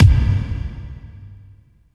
33.10 KICK.wav